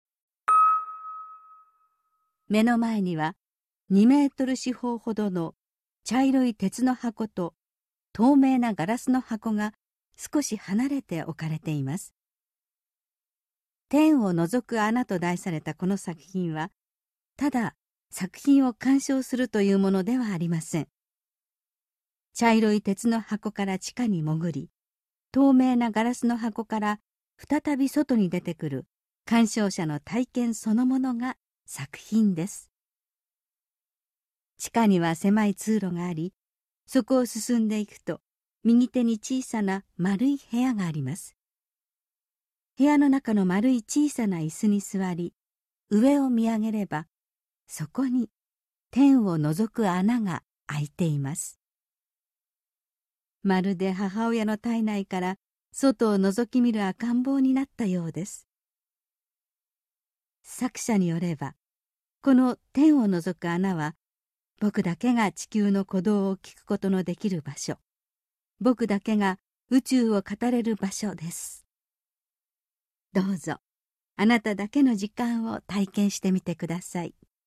箱根 彫刻の森美術館 THE HAKONE OPEN-AIR MUSEUM - 音声ガイド - 井上武吉 my sky hole 79 天をのぞく穴 1979年